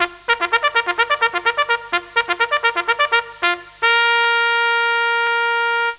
Trumpet1
Trumpet1.wav